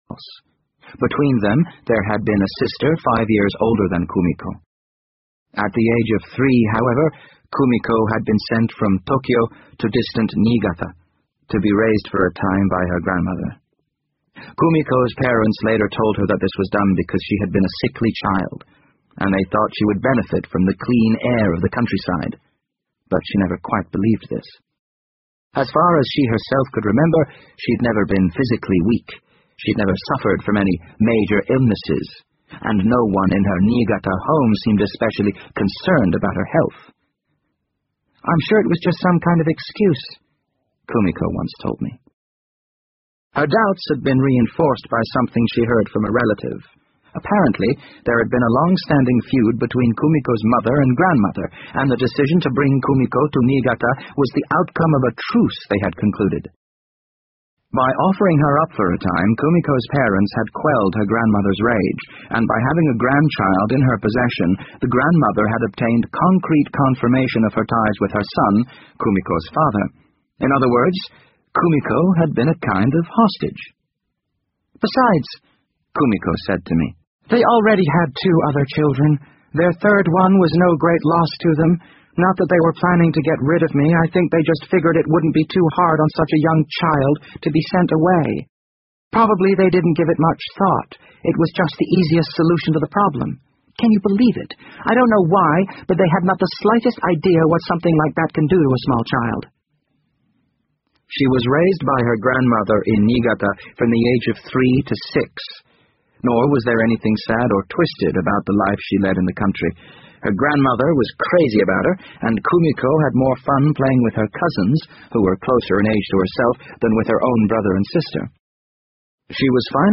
BBC英文广播剧在线听 The Wind Up Bird 34 听力文件下载—在线英语听力室